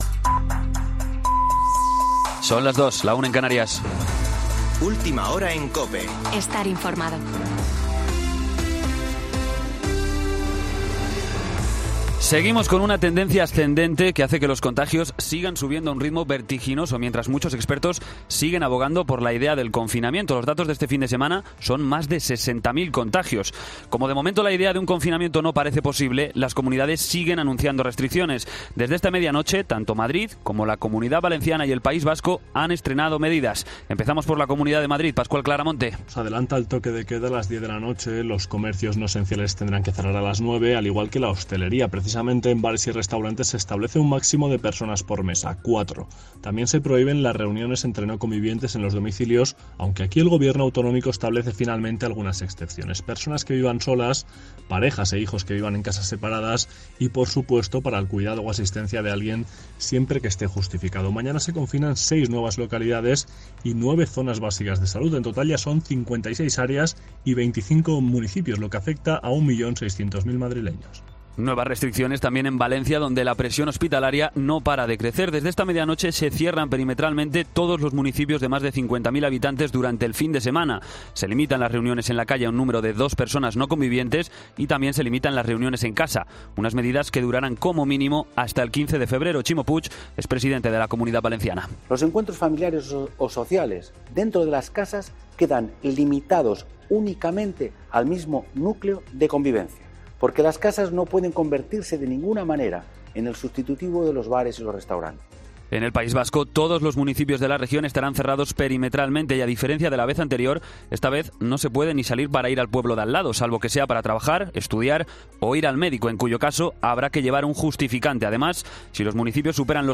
Boletín de noticias COPE del 25 de enero de 2021 a las 02.00 horas